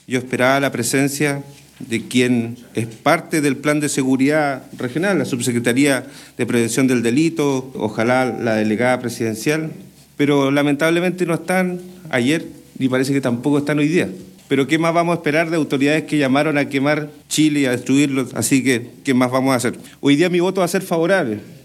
Así lo planteó el consejero Fernando Hernández.